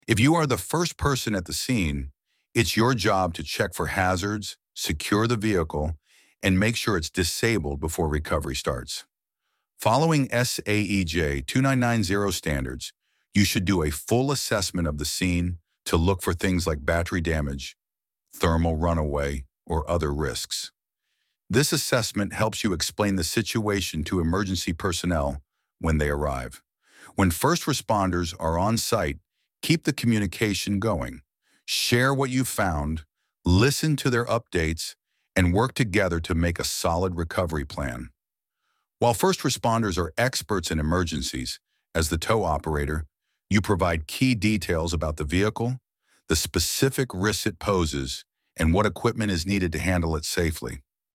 ElevenLabs_Topic_1.2.2.mp3